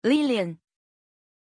Pronunciation of Liliann
pronunciation-liliann-zh.mp3